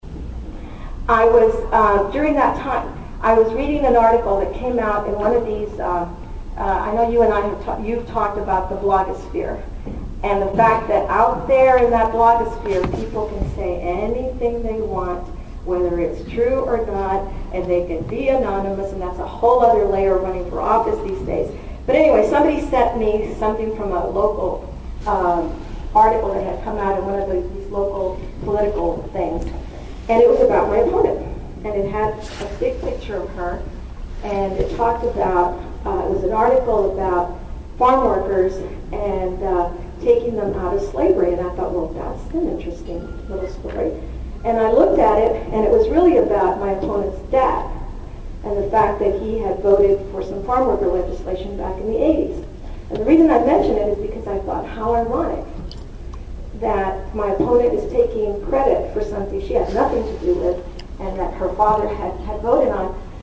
Judge Yanez gave a speech last week.